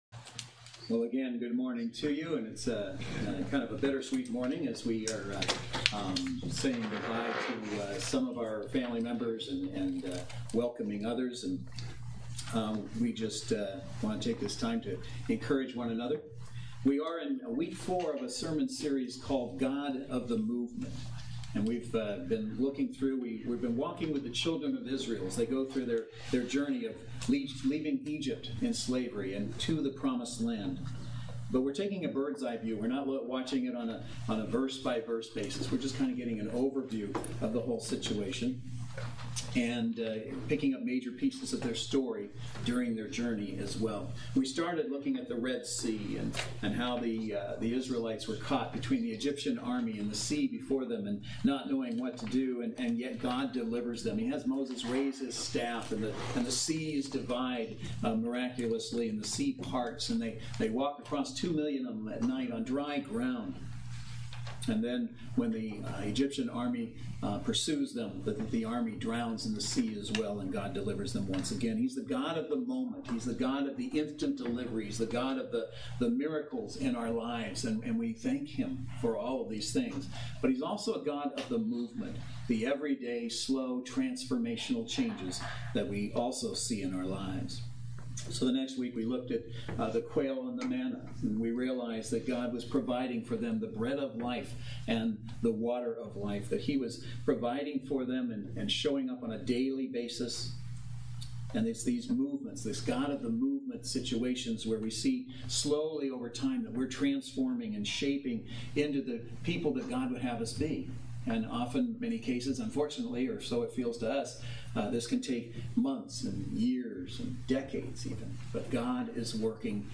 God of the Movement Service Type: Saturday Worship Service Speaker